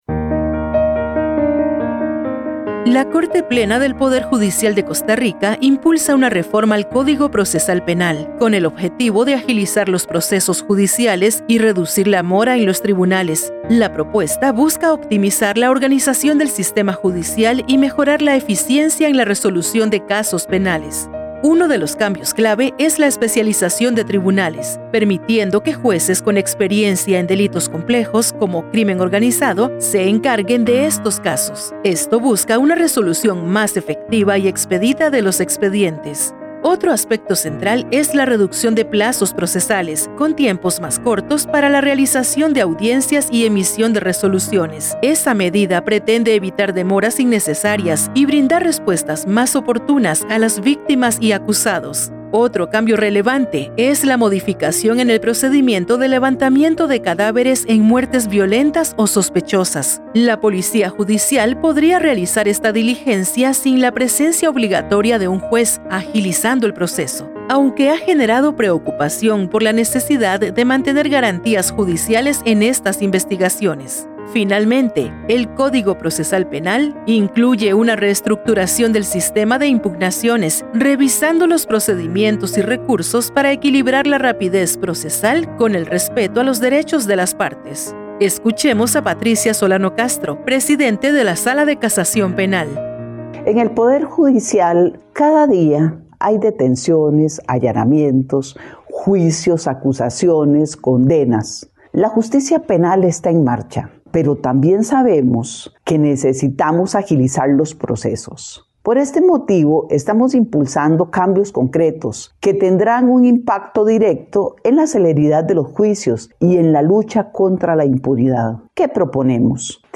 Escuchemos a Patricia Solano Castro, presidente de la Sala de Casación Penal .